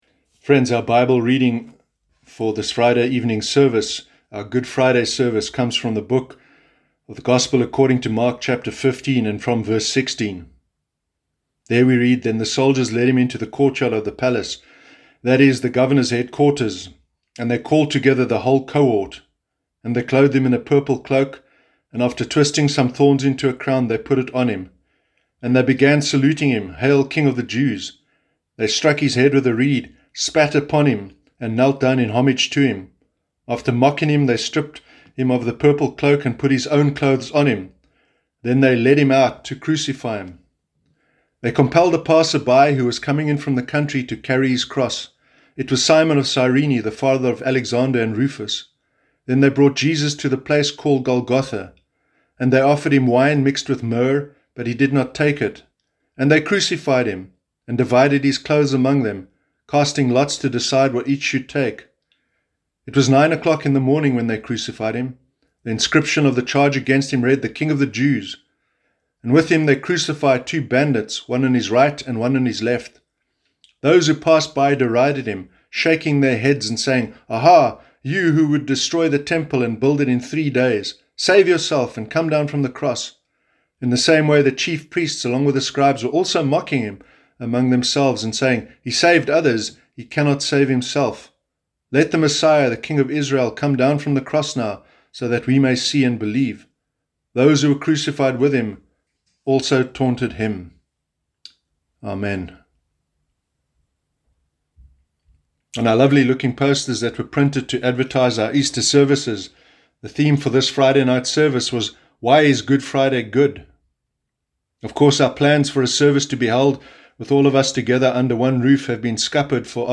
Welcome to everyone able to join us online for our Good Friday evening service this year.